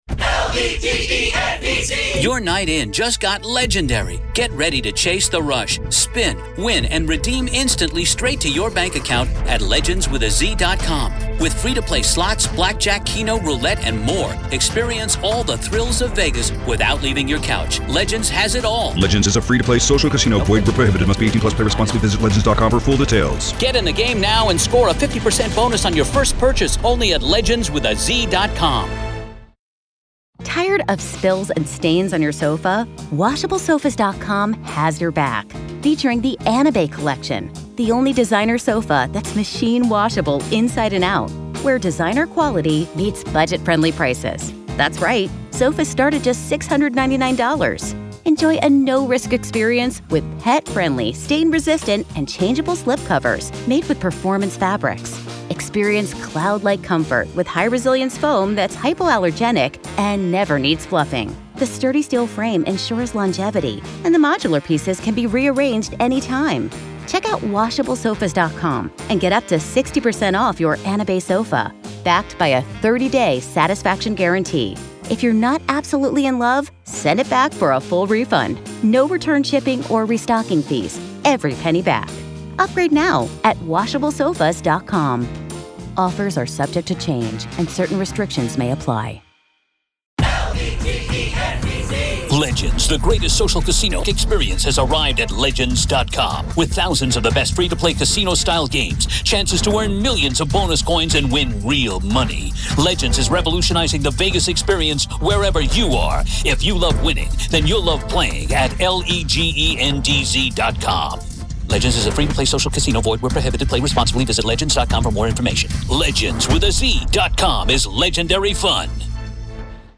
True Crime Today | Daily True Crime News & Interviews